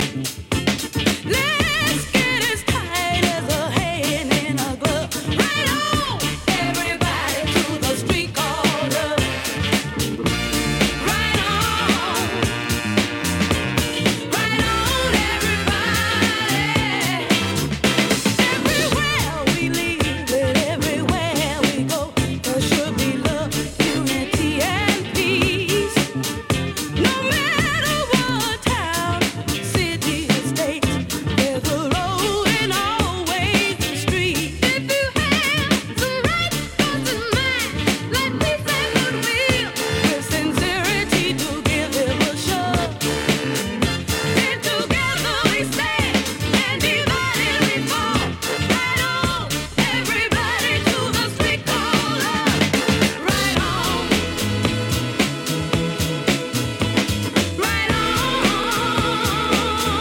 classic funky soul